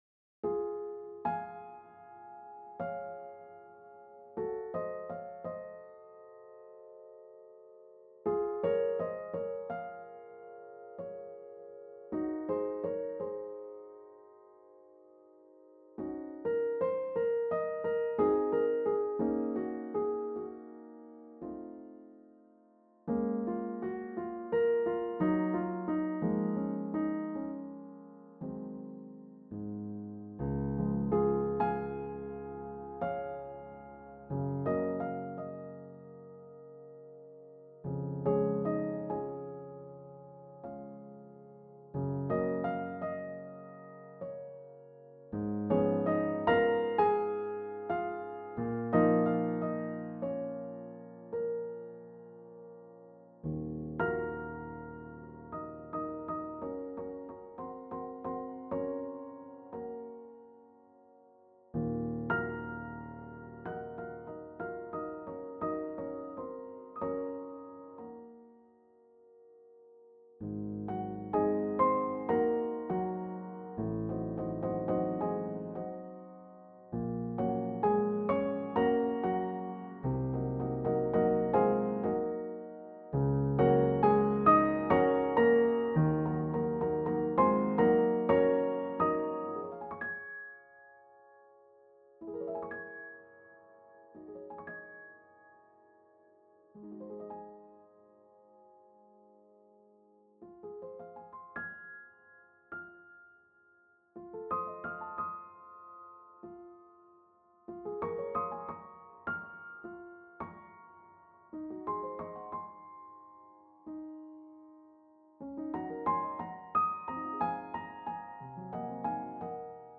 The pieces in this collection are my versions of some well-known piano compositions or extracts thereof.1  What struck me about each of them is how much the composer was able to say in just a few notes, a mere fistful of them.
As odd as this may sound, I didn't actually play any of these pieces on a piano. The performances were put together on a computer, where my job was to decide for each note just when it should hit, how hard, and for how long.2